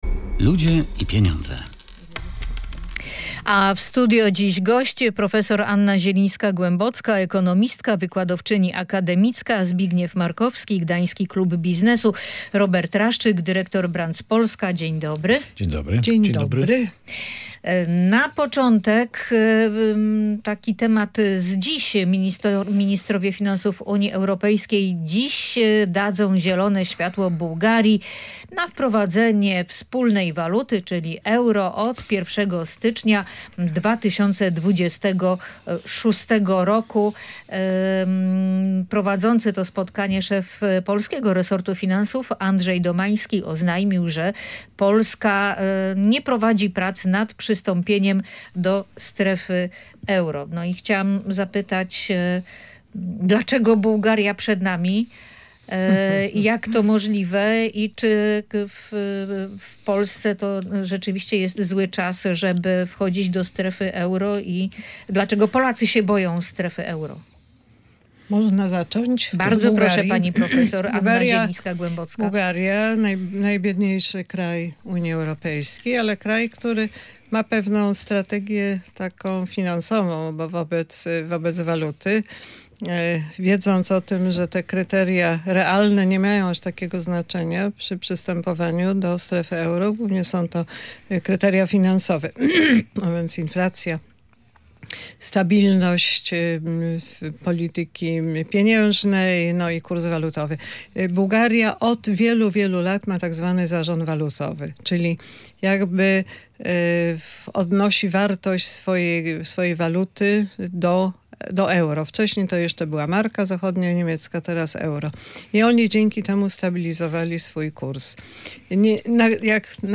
Na temat możliwych korzyści i zagrożeń wynikających z przyjęcia euro przez Polskę dyskutowali goście